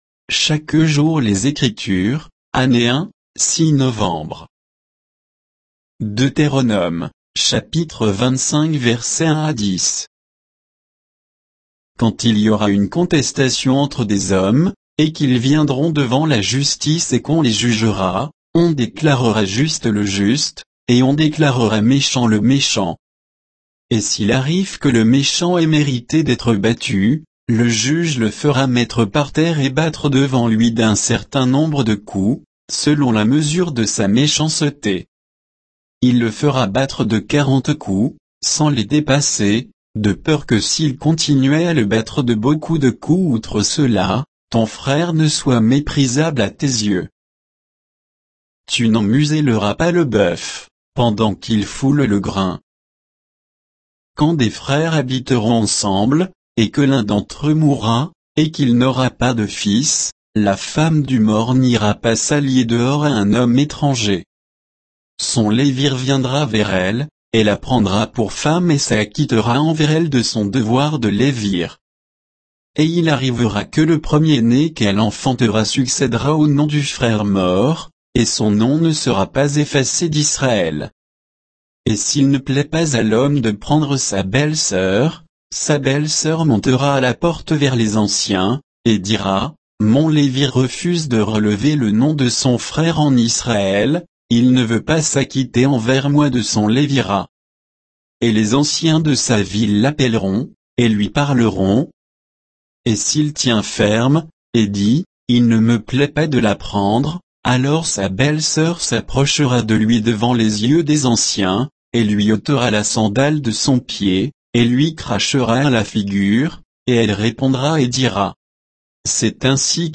Méditation quoditienne de Chaque jour les Écritures sur Deutéronome 25, 1 à 10